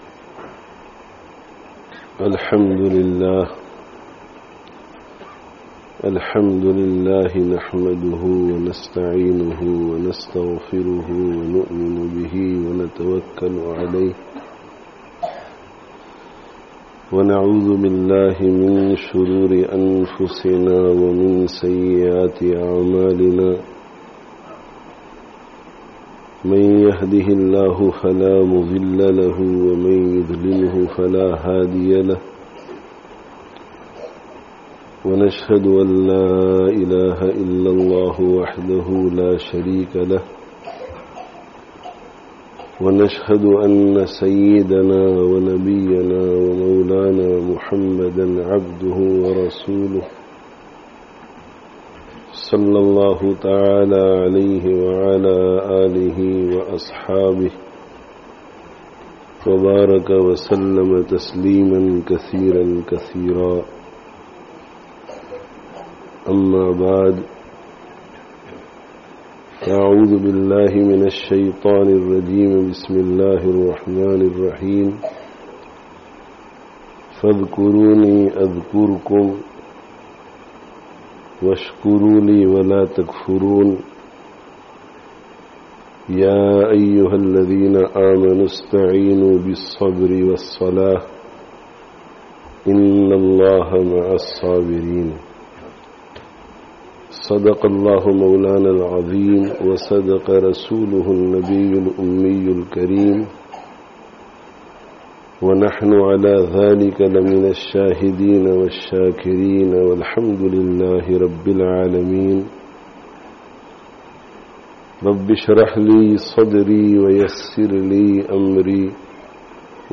How to Make Life Valuable [Annual Youth Conference for Yorkshire] (Jame Masjid, Batley 11/01/20)